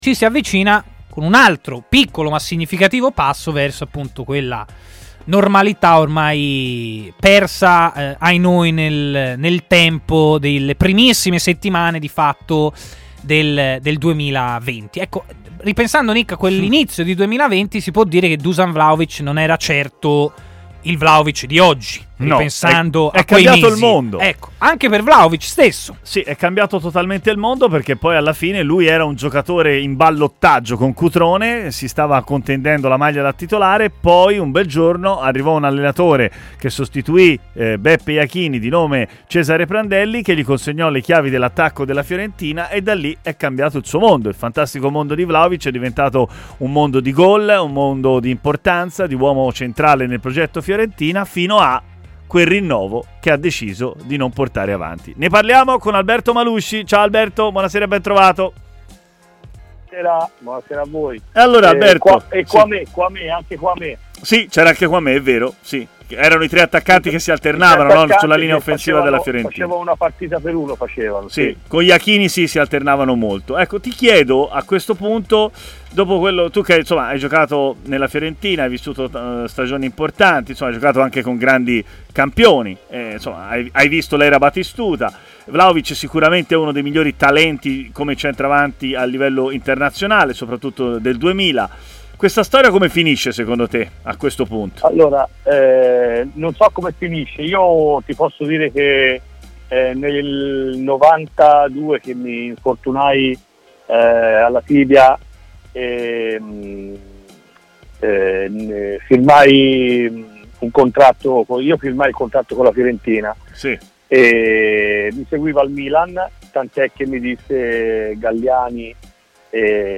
è stato ospite a Stadio Aperto, trasmissione pomeridiana di TMW Radio